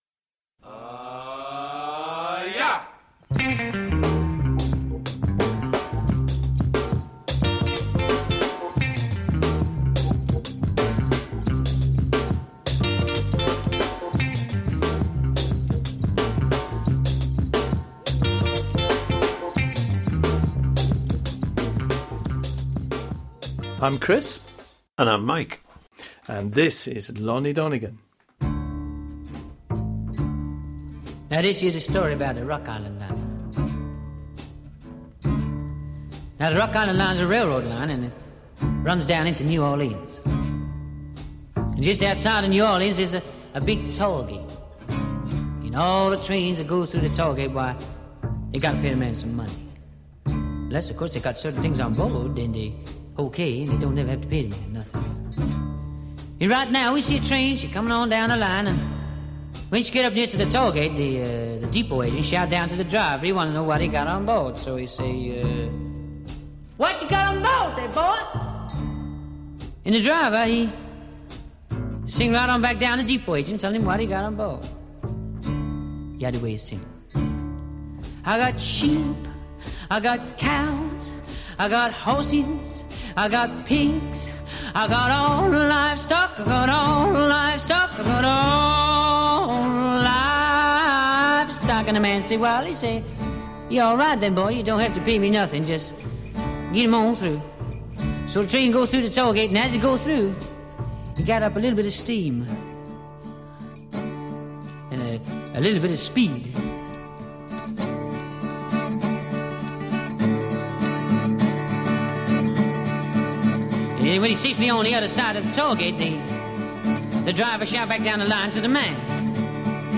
If you like Rock Music then you might like this Radio Show we put together on the subject of Merseybeat. We recorded it in a studio after having worked on it for about a year.